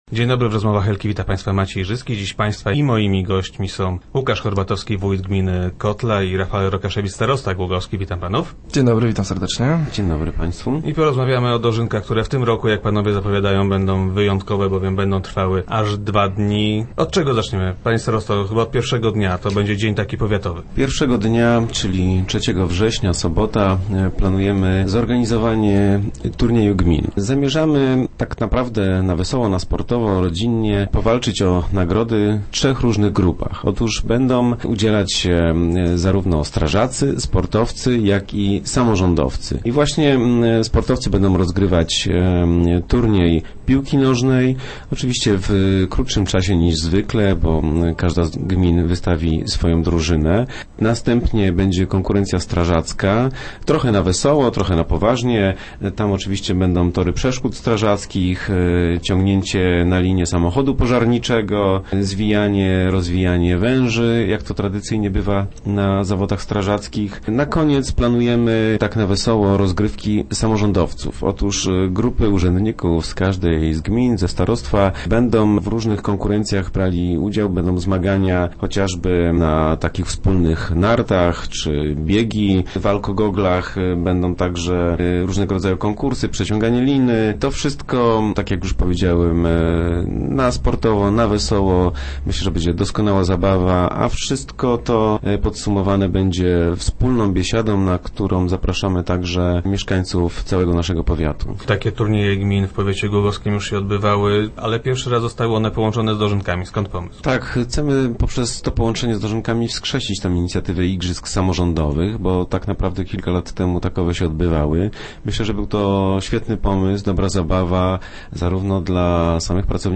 Gośćmi Rozmów Elki byli Łukasz Horbatowski, wójt gminy Kotla oraz starosta Rafael Rokaszewicz.